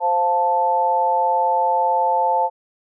이명소리의 종류
이명소리 2
▶ 누르면 해당 이명소리가 들립니다.